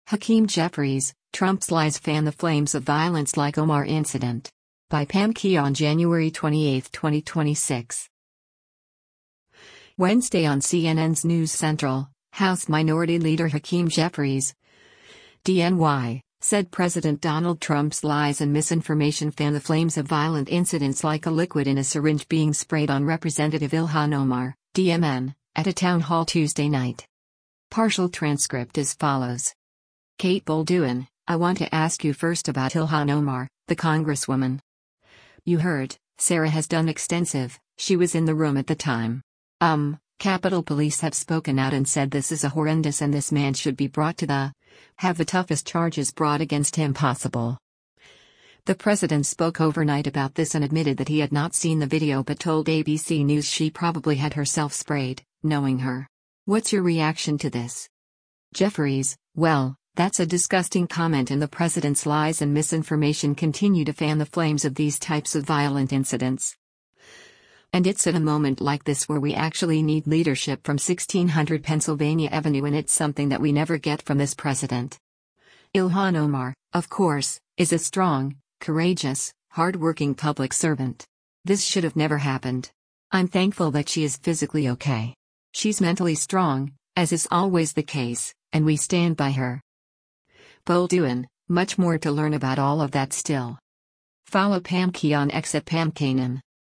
Wednesday on CNN’s “News Central,” House Minority Leader Hakeem Jeffries (D-NY) said President Donald Trump’s “lies and misinformation” fan the flames of violent incidents like a liquid in a syringe being sprayed on Rep. Ilhan Omar (D-MN) at a town hall Tuesday night.